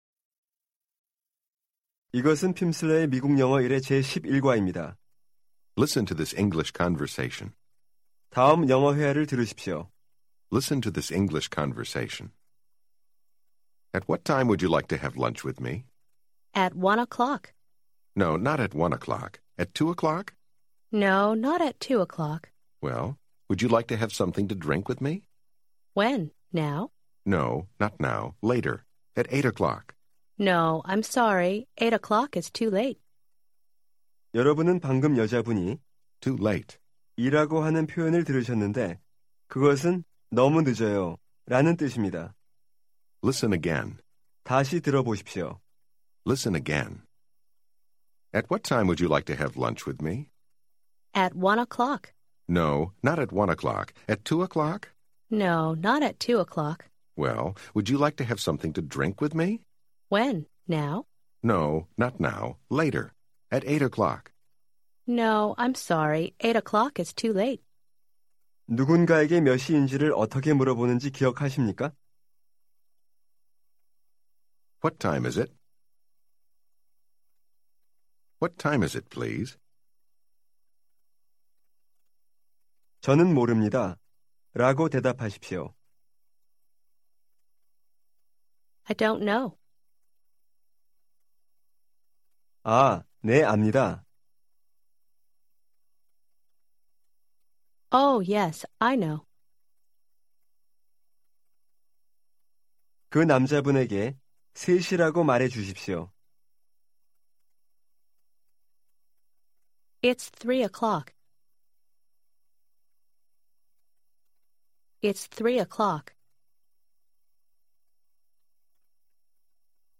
Audiobook
This course includes Lessons 11-15 from the English for Korean Speakers Level 1 30-Lesson Program featuring 2.5 hours of language instruction. Each lesson provides 30 minutes of spoken language practice, with an introductory conversation, and new vocabulary and structures.